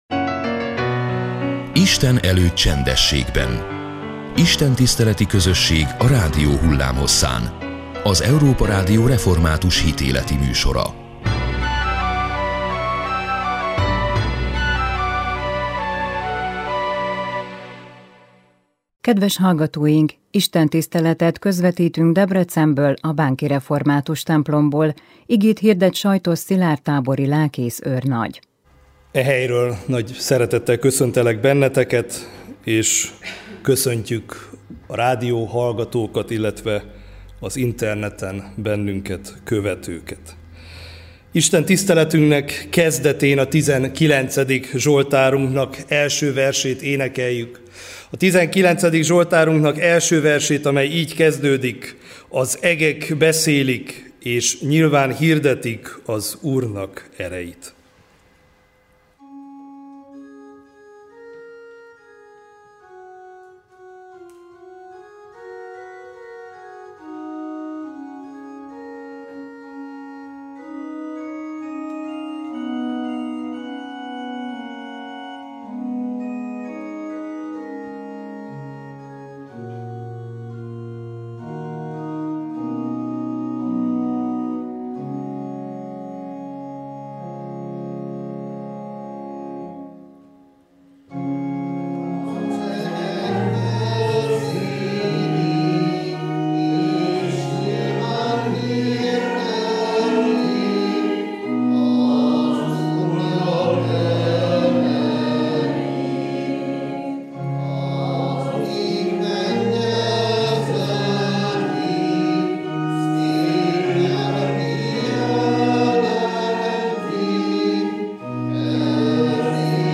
Istentiszteleti közösség a rádió hullámhosszán. Az Európa Rádió hitéleti műsora minden vasárnap és a református egyház ünnepnapjain.